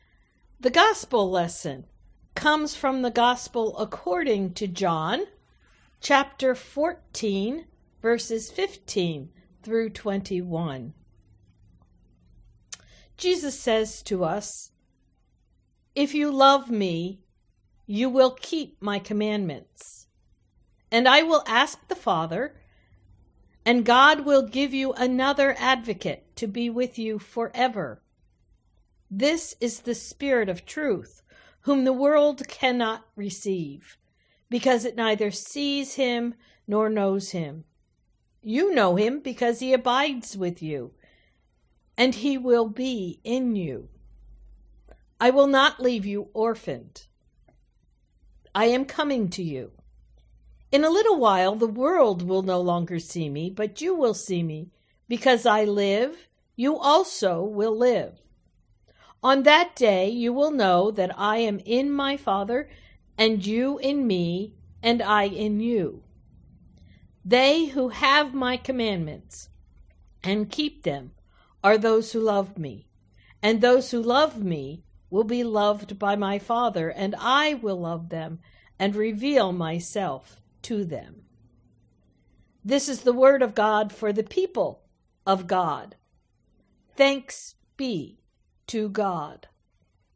Gospel Reading: